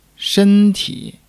shen1--ti3.mp3